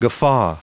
Prononciation du mot guffaw en anglais (fichier audio)
Prononciation du mot : guffaw